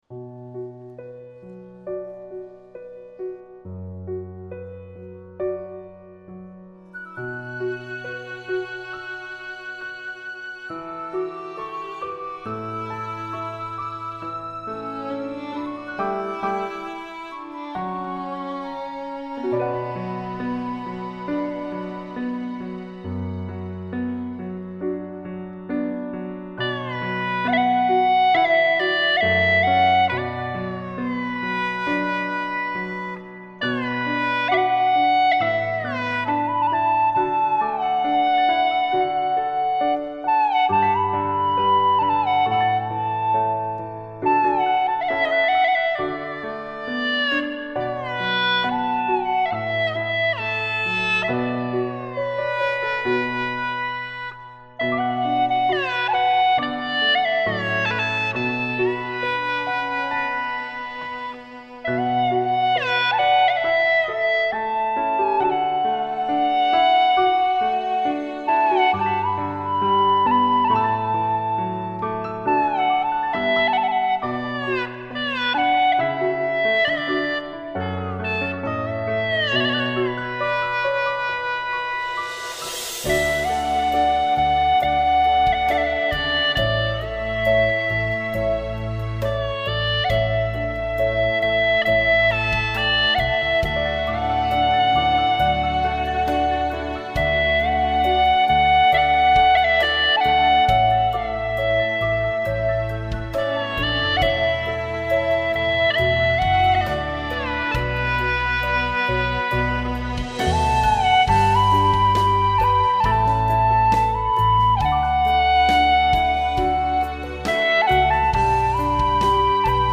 调式 : D 曲类 : 独奏